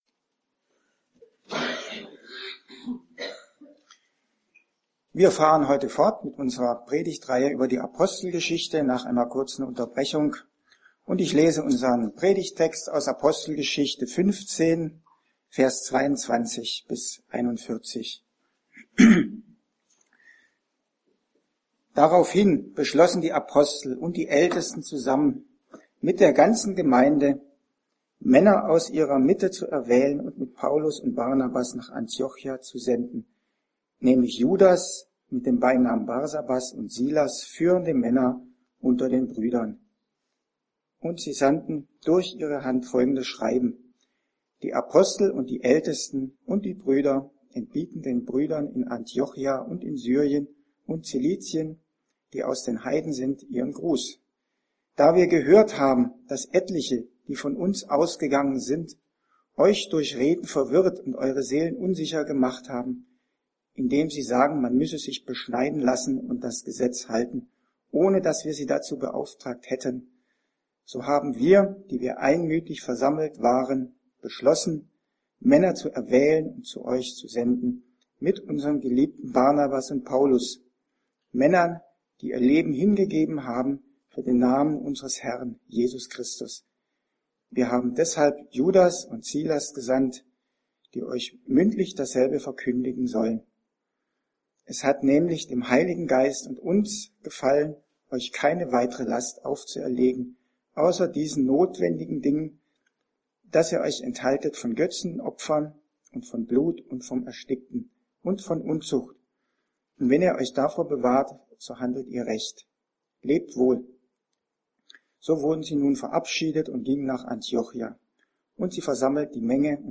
Gal 4,21-31 Dienstart: Predigt